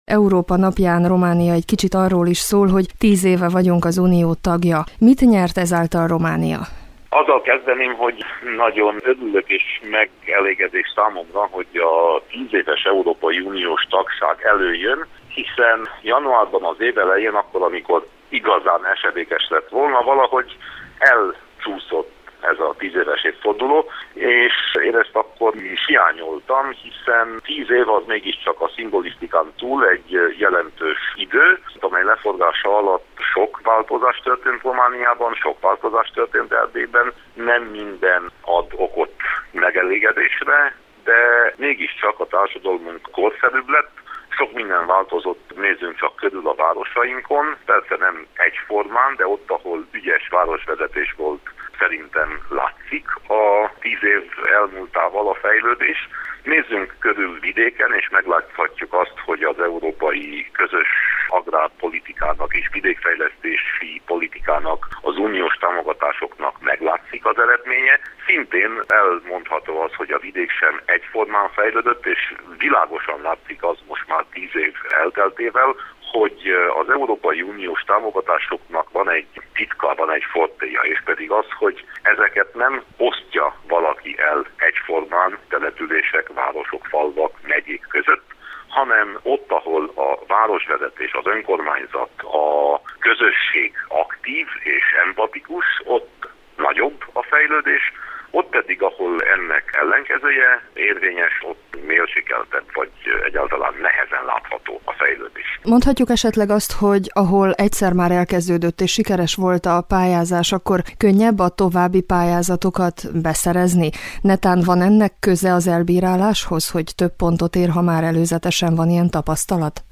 Európa-napi beszélgetés Winkler Gyulával – Bukaresti Rádió